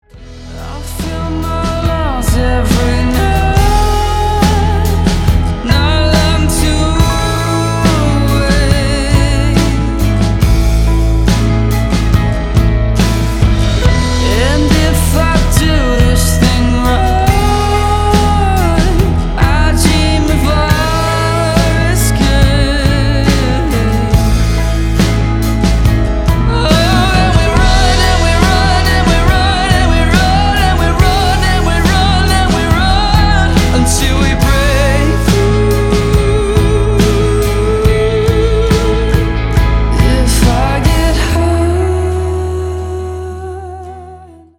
• Качество: 320, Stereo
гитара
мужской вокал
мелодичные
спокойные
чувственные
Alternative Rock
медленные
романтичные
indie rock